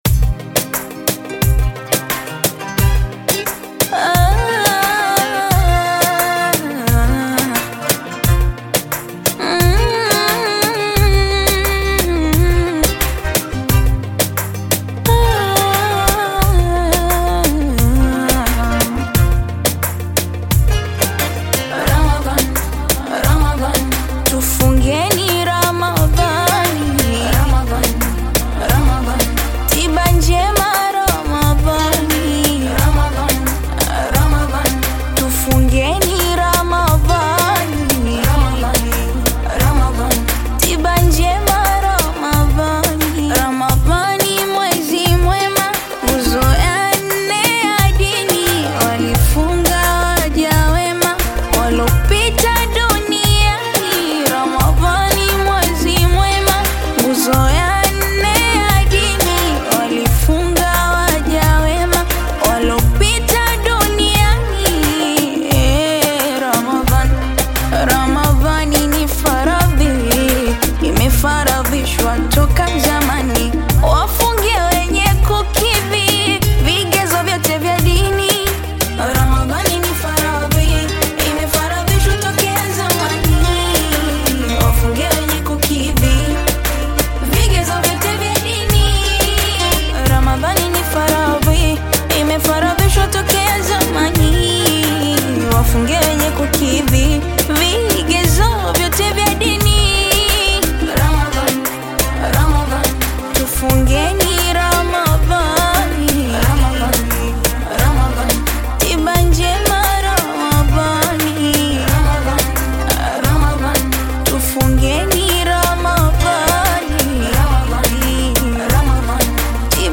Qaswida music track
Qaswida song